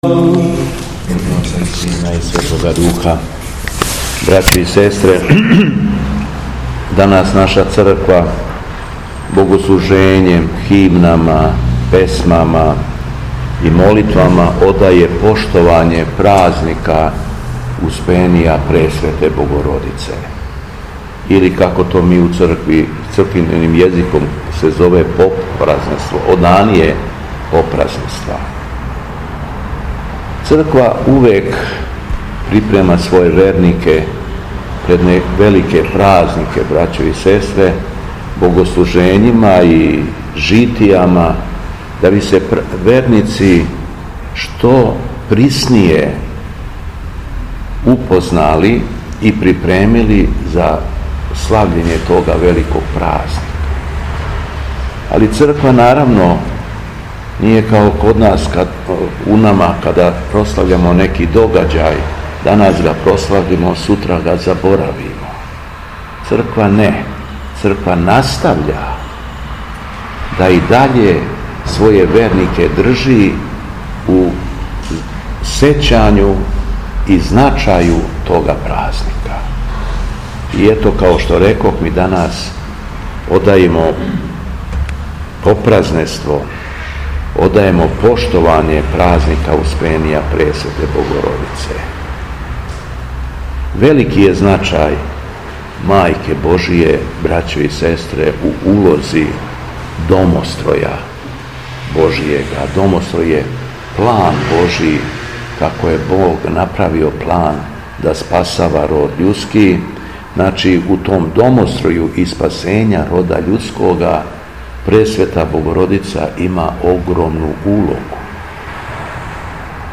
У уторак, 18. јула 2023. године, када се наша Црква молитвено сећа преподобног Атанасија атонског и светог Сергија радоњешког, Његово Преосвештенство Епископ шумадијски г. Јован служио је Свету Архијерејску Литургију у храму Светог великомученика Димитрија у крагујевачком насељу Сушица уз саслужење...
Беседа Његовог Преосвештенства Епископа шумадијског г. Јована
После прочитаног јеванђелског зачала преосвећени владика Јован се обратио верном народу беседом: